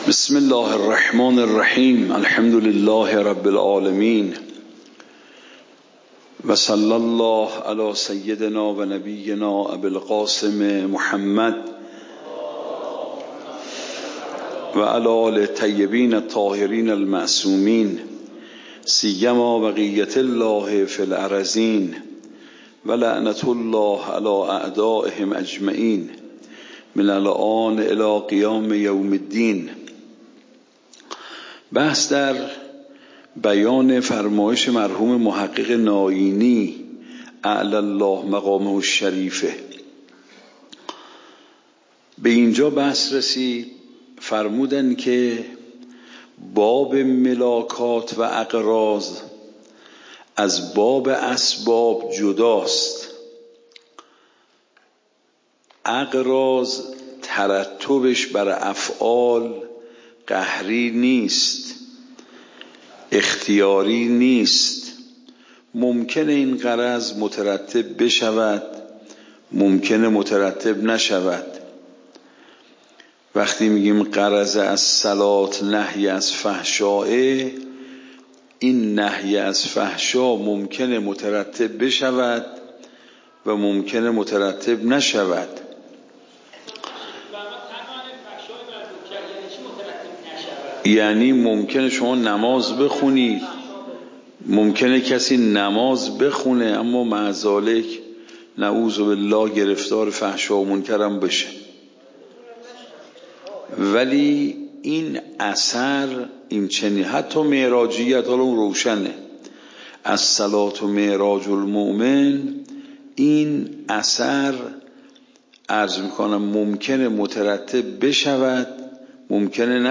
درس بعد تعبدی و توصلی درس قبل تعبدی و توصلی درس بعد درس قبل موضوع: واجب تعبدی و توصلی اصول فقه خارج اصول (دوره دوم) اوامر واجب تعبدی و توصلی تاریخ جلسه : ۱۴۰۴/۲/۷ شماره جلسه : ۸۳ PDF درس صوت درس ۰ ۳۲۹